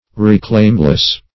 Reclaimless \Re*claim"less\, a.
reclaimless.mp3